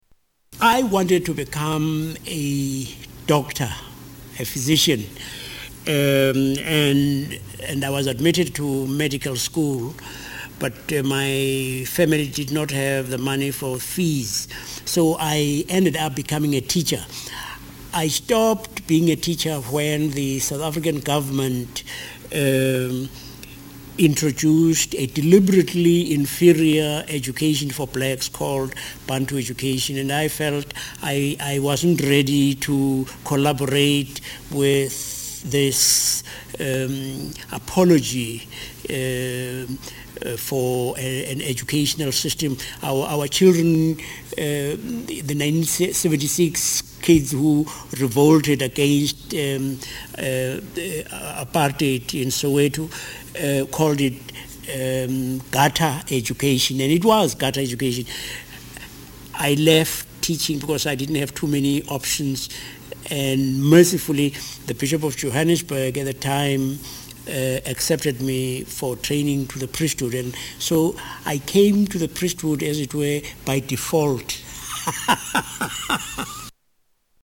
Tags: Historical ArchBishop Desmond Tutu ArchBishop Desmond Tutu Interview Clips Apartheid African